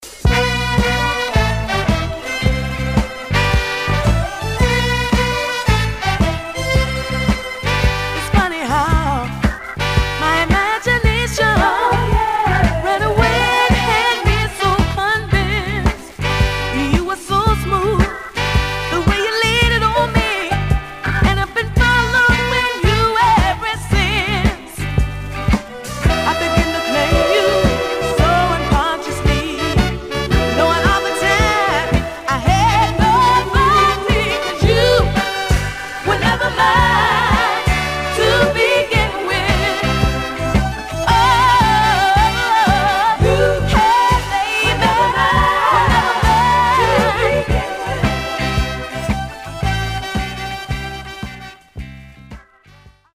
Some surface noise/wear Stereo/mono Mono